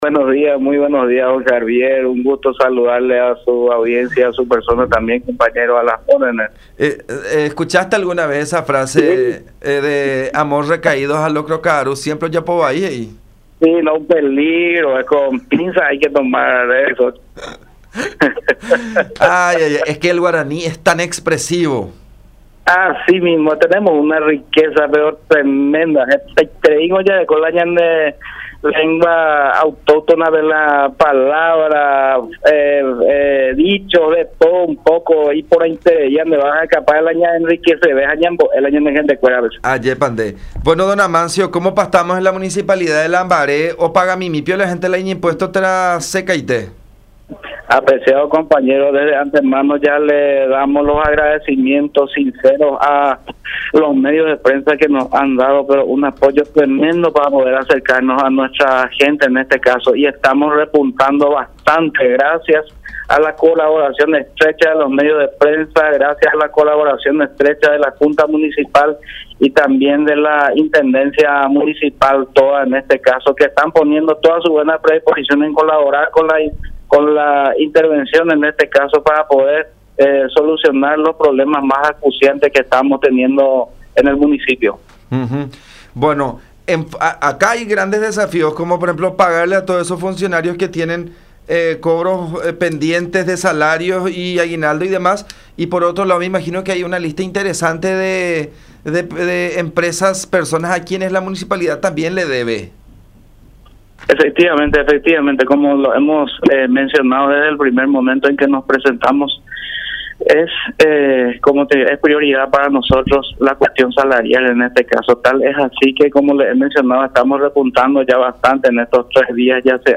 Así lo informó Amancio Rivas, interventor de la municipalidad de Lambaré, quien afirmó que lo recaudado es destinado de manera urgente al pago de salarios de los funcionarios, de los cuales algunos no perciben desde hace 7 e incluso 8 meses.